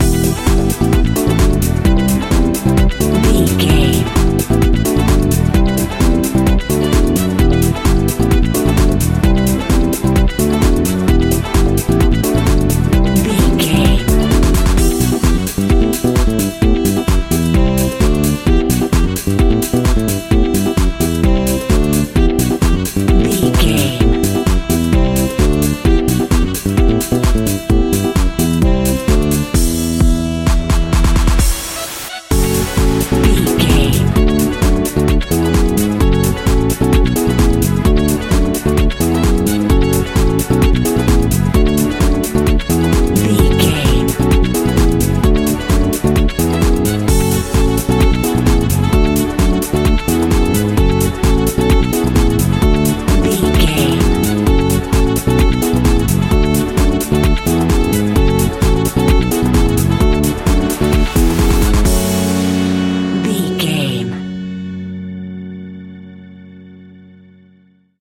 Aeolian/Minor
F#
uplifting
driving
energetic
funky
saxophone
bass guitar
drums
synthesiser
electric organ
funky house
deep house
nu disco
upbeat
instrumentals